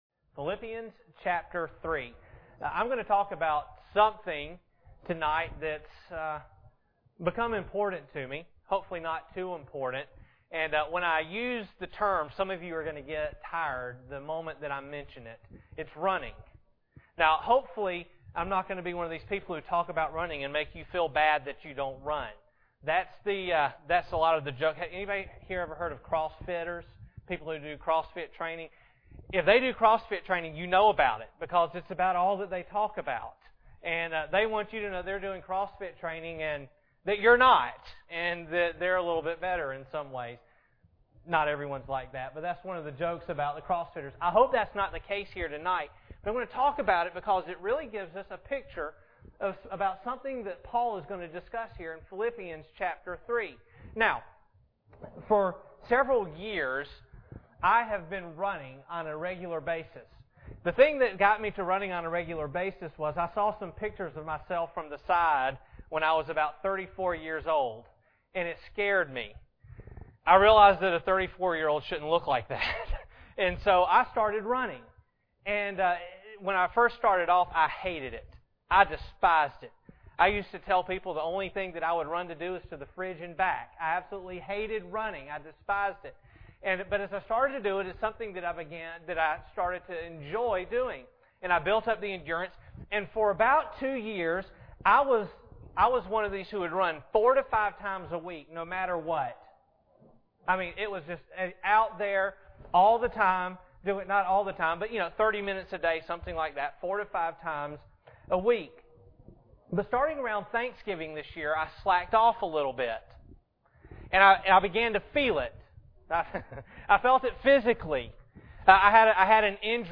Passage: Philippians 3:7-16 Service Type: Sunday Evening